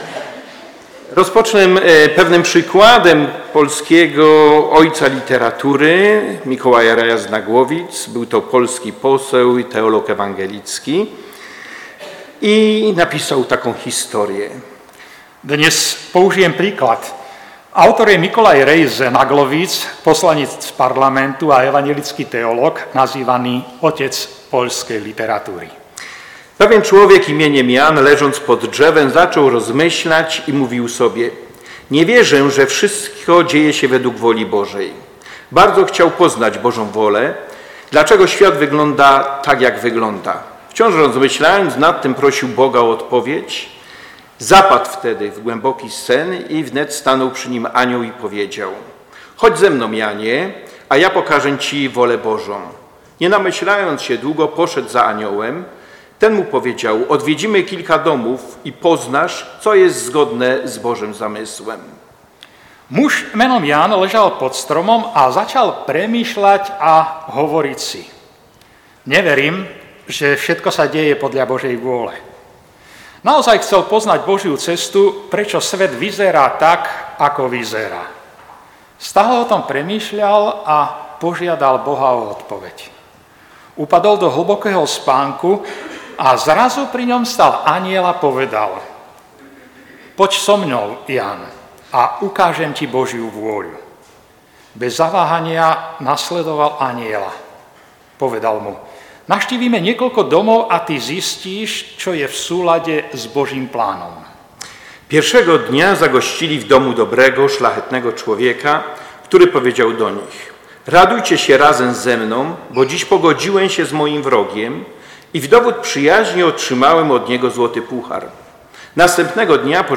Božia vôľa (L 1, 5-25) káže